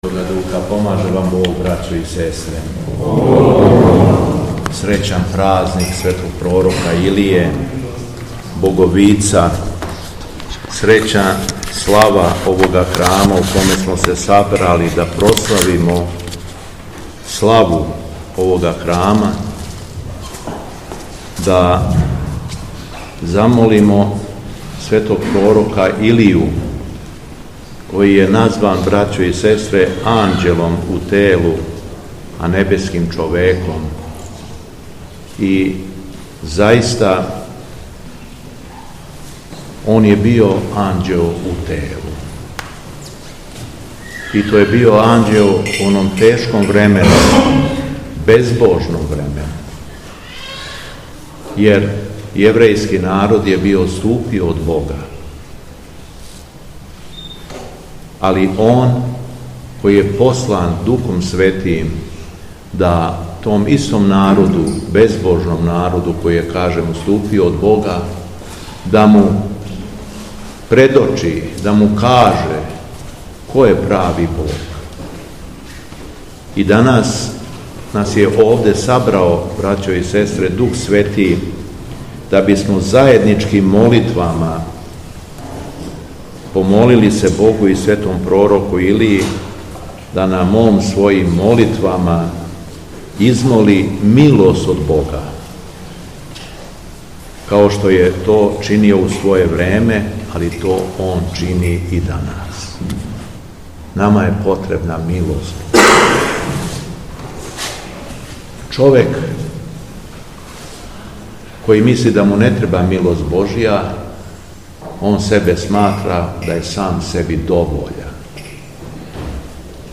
Беседа Његовог Високопреосвештенства Митрополита шумадијског г. Јована
Након читања Јеванђелског одељка Митрополит Јован је верима честитао храмовну славу и поучио их својом беседом: